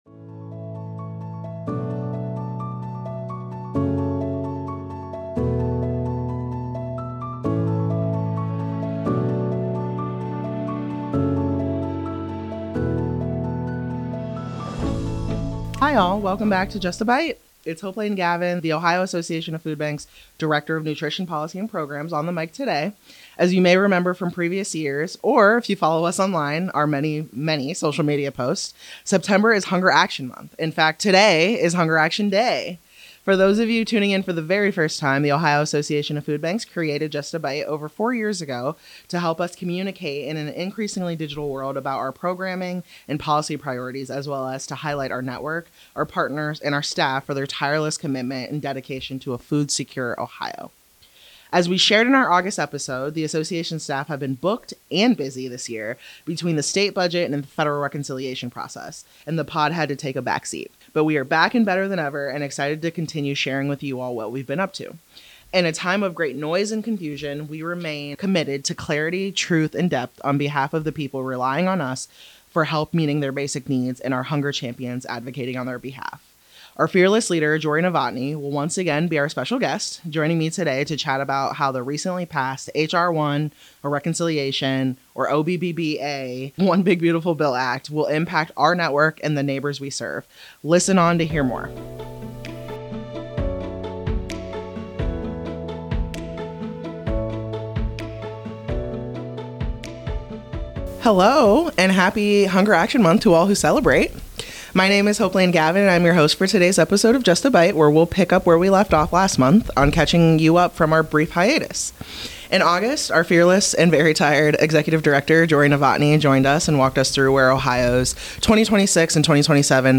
Hunger Action Day Conversation: The Future of SNAP, Medicaid, and Ohio Families